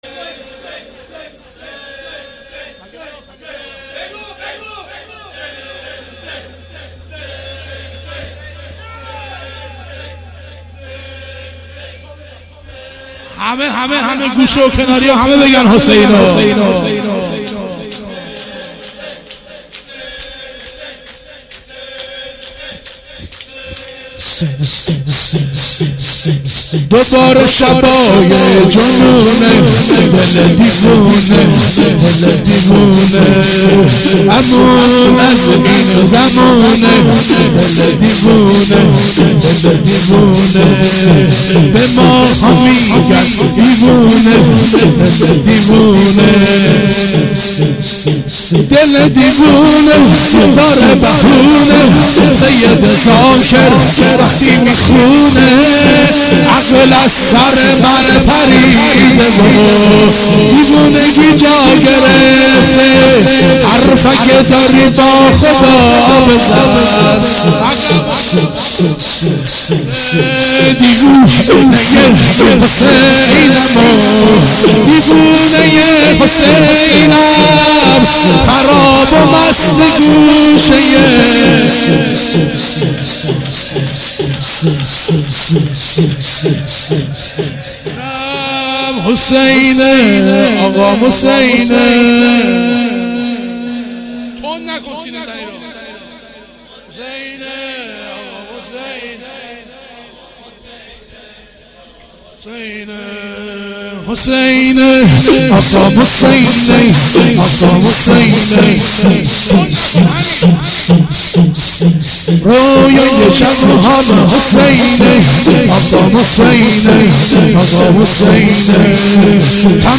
شور
شب دوم محرم 96